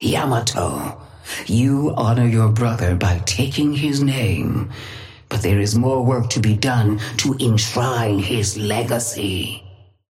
Sapphire Flame voice line - Yamato, you honor your brother by taking his name, but there is more work to be done to enshrine his legacy.
Patron_female_ally_yamato_start_01.mp3